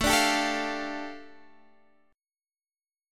A7sus4#5 chord